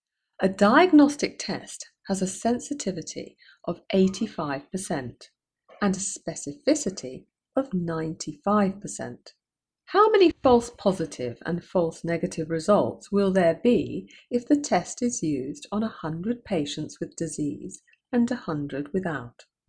Narration audio (MP4) Narration audio (OGG) Type the correct answers into the boxes below.